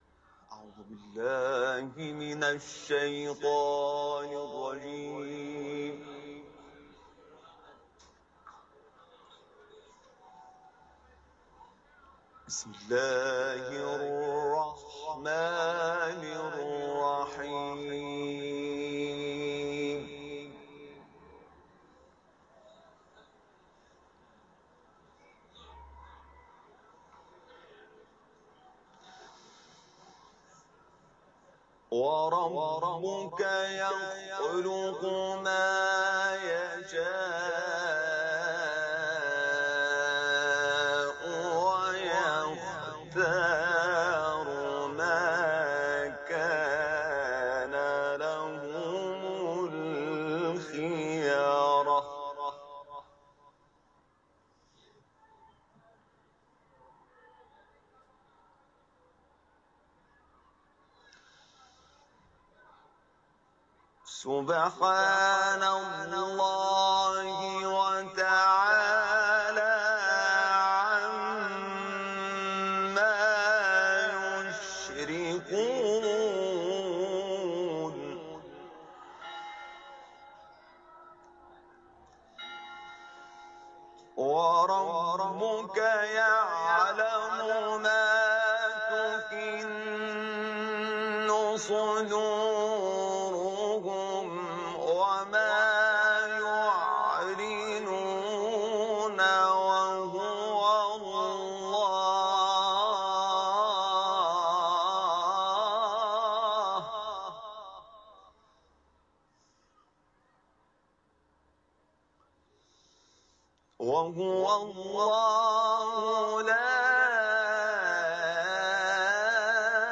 تلاوت ، سوره قصص ، حرم مطهر رضوی